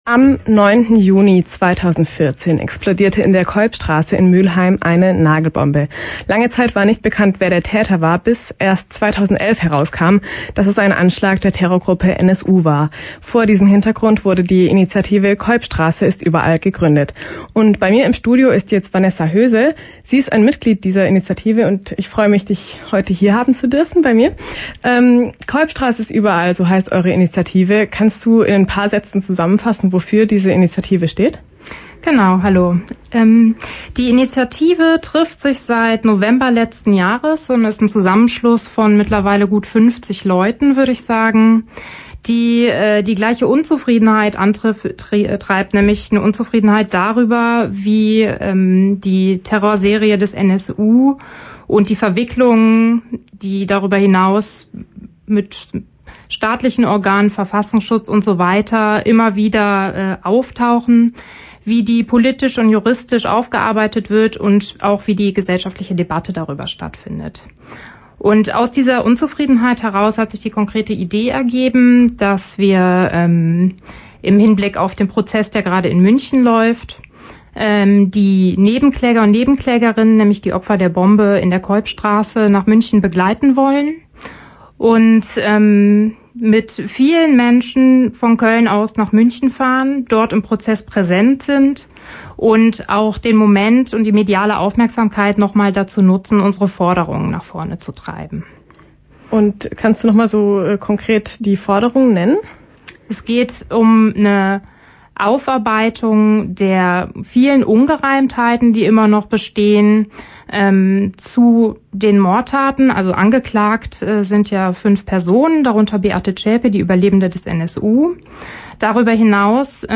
Presseschau: Radiointerview KölnCampus